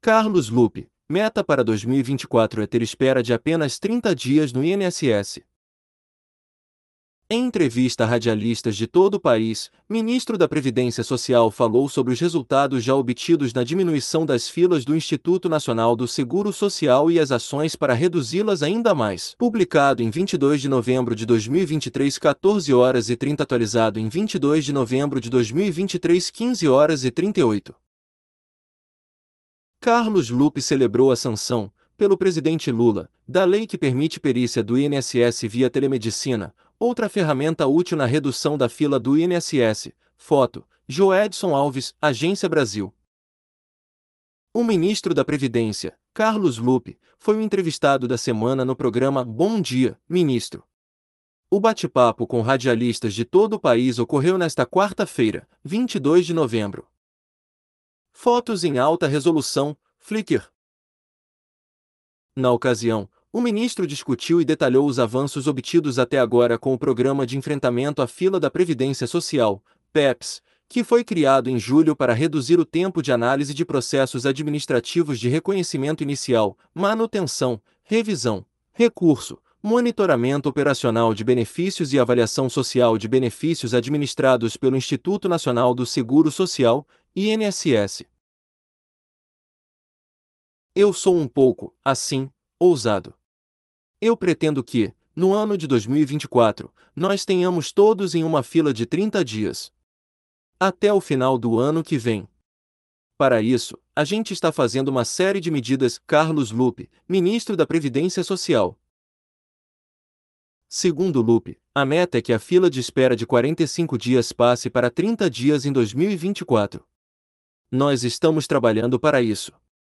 Em entrevista a radialistas de todo o país, ministro da Previdência Social falou sobre os resultados já obtidos na diminuição das filas do Instituto Nacional do Seguro Social e as ações para reduzi-las ainda mais